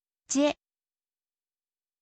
ออกเสียง: je, เจะ
เป็นเสียงเดียวกันกับ “ぢぇ” เสียงนี้ใกล้เคียงกับ “เจะ” แต่เป็น “je” ในภาษาอังกฤษ ตั้งใจฟังเสียงและเลียนแบบกันเถอะ